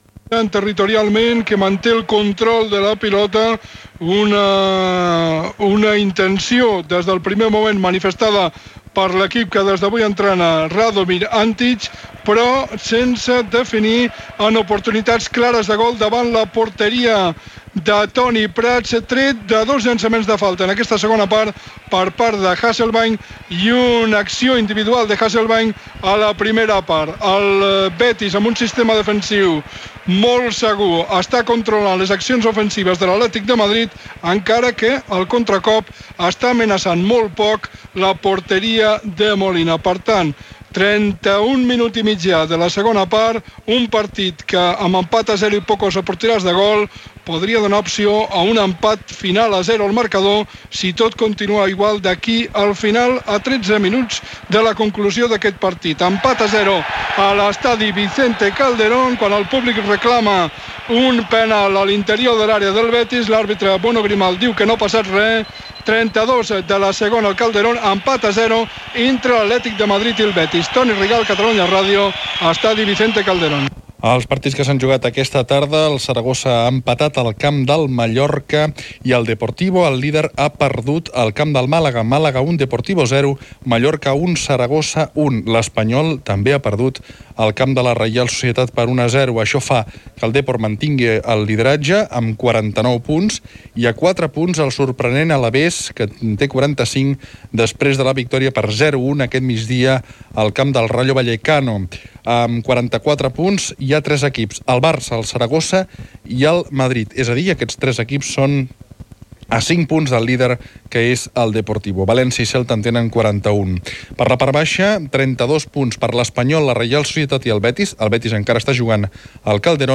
Connexió amb el partit Atlético de Madrid Betis, resultats de la lliga, travessa, hoquei patins, careta de sortida, indicatiu de l'emissora.
Informatiu